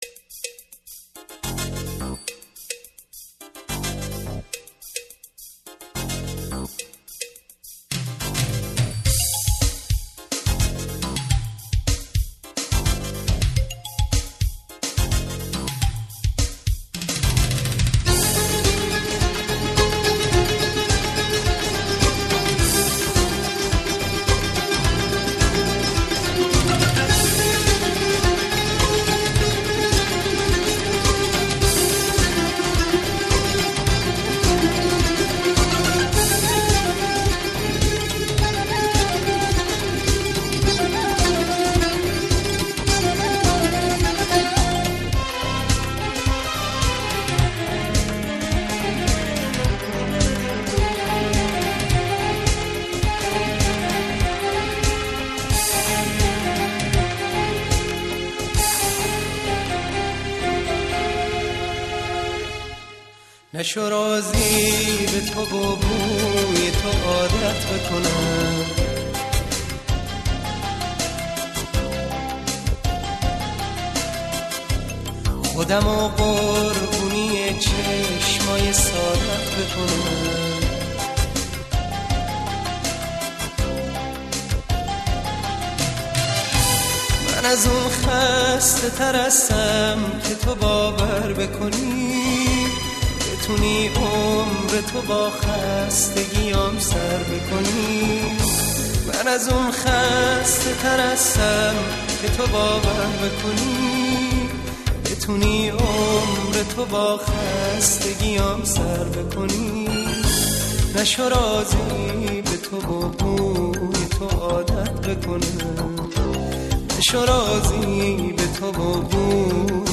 خوانندهٔ سرشناس موسیقی پاپ ایرانی بود.
صدای محزون و دلنشینی داشت.